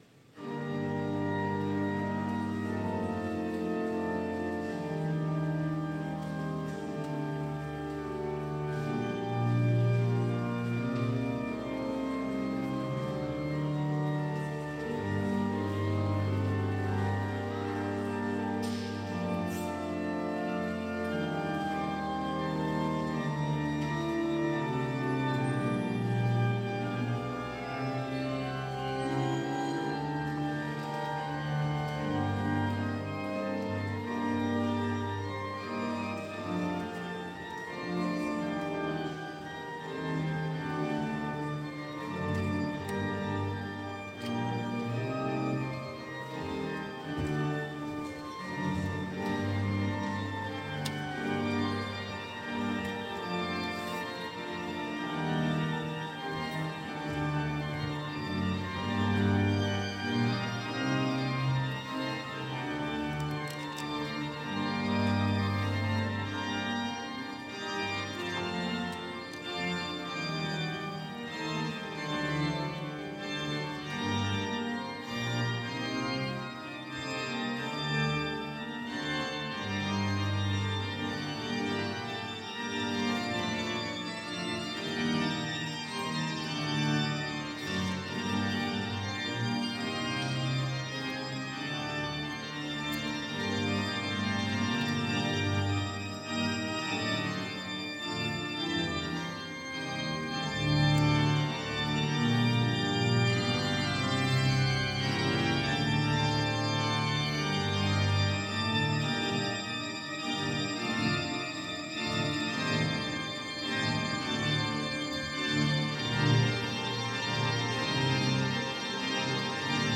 Écouter le culte entier (Télécharger au format MP3)